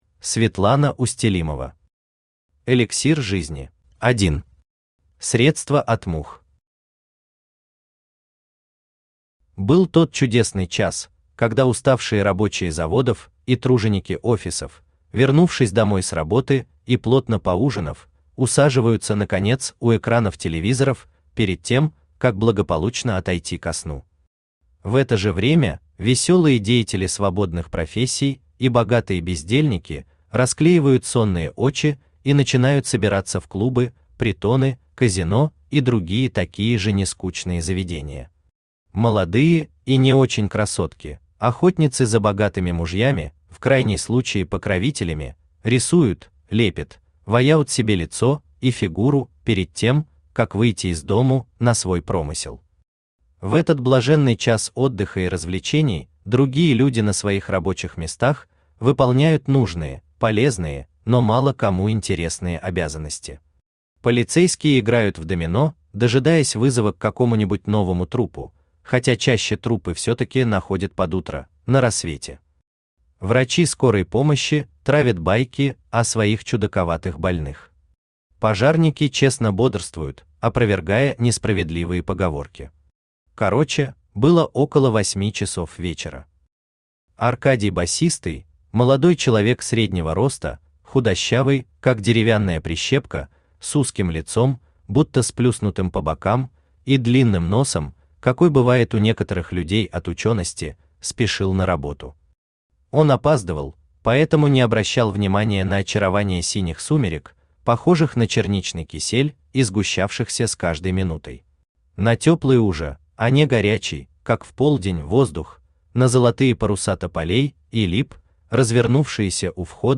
Аудиокнига Эликсир жизни | Библиотека аудиокниг
Aудиокнига Эликсир жизни Автор Светлана Борисовна Устелимова Читает аудиокнигу Авточтец ЛитРес.